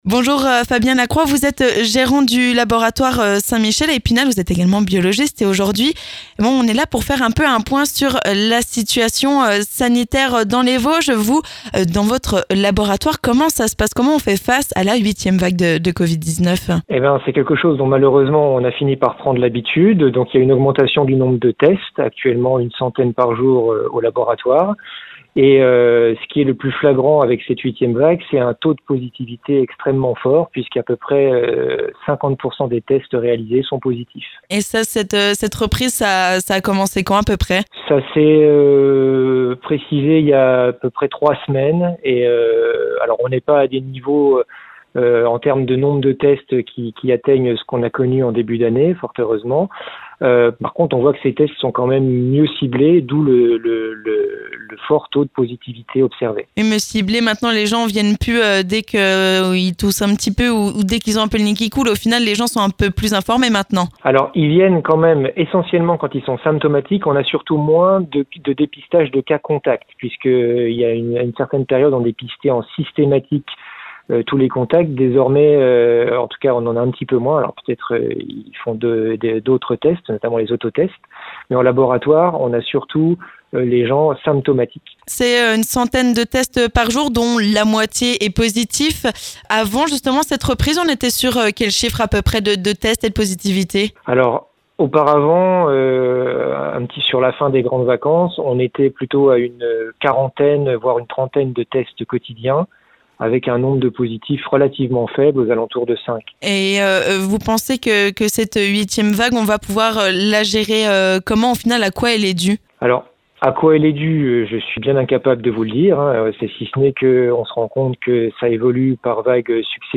COVID-19 : reprise, hausse des cas... on fait le point avec un biologiste spinalien !